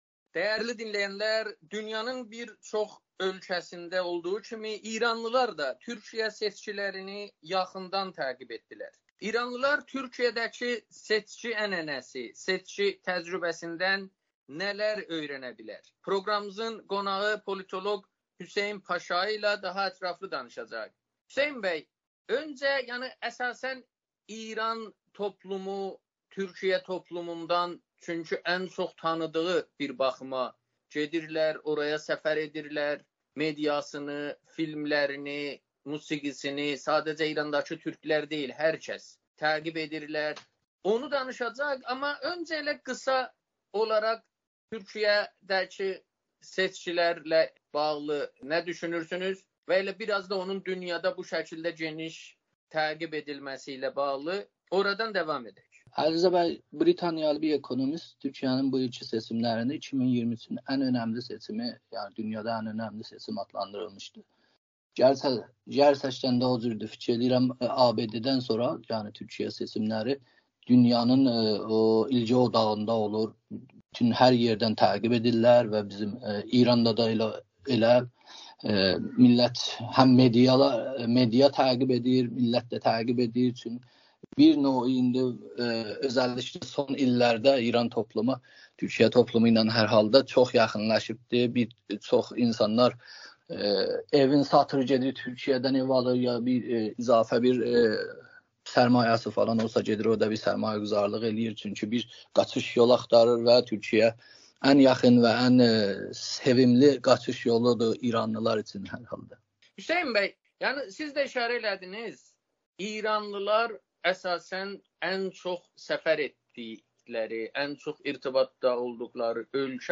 Türkiyədə 2023-cü il prezident seçkiləri bölgənin digər ölkələrində olduğu kimi İranda da xüsusilə ziyalılar və siyasi aktivistlər tərəfindən diqqətlə təqib edilib. Amerikanın Səsinə danışan politoloq